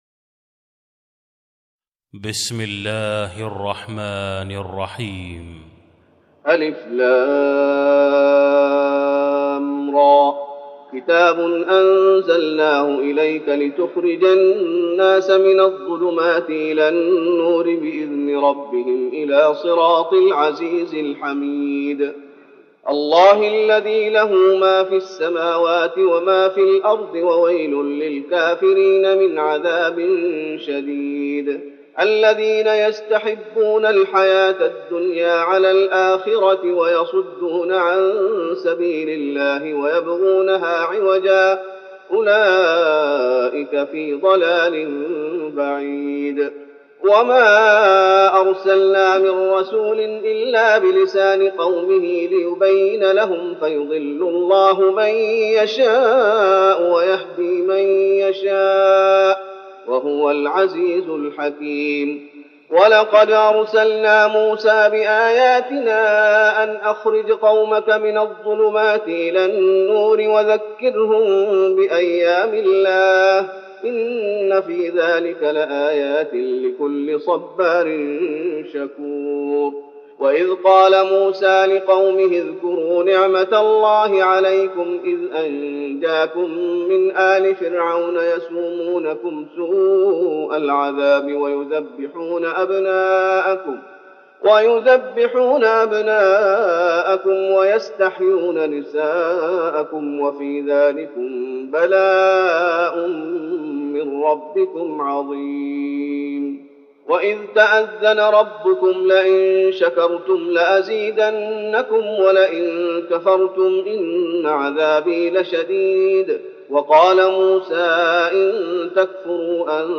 تراويح رمضان 1413هـ سورة إبراهيم Taraweeh Ramadan 1413H from Surah Ibrahim > تراويح الشيخ محمد أيوب بالنبوي 1413 🕌 > التراويح - تلاوات الحرمين